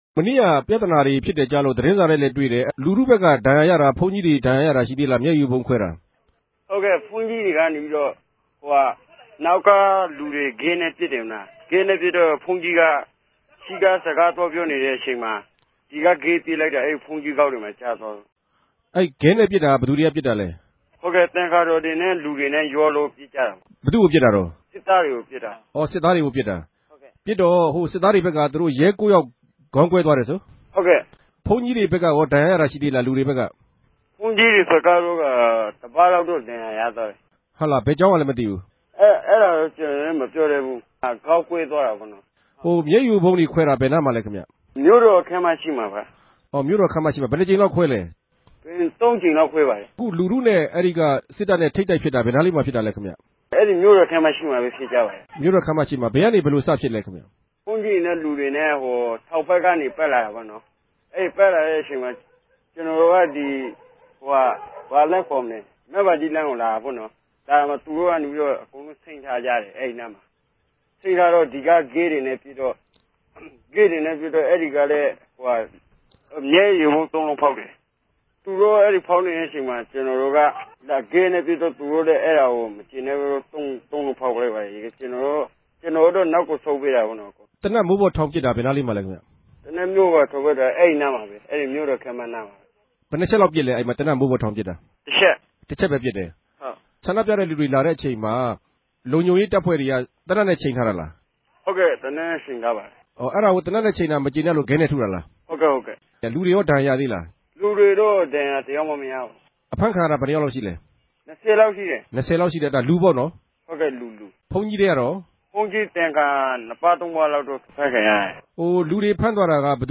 ဗန်ကောက် RFA႟ုံးခြဲကနေ ဆက်သြယ် မေးူမန်းထားပၝတယ်။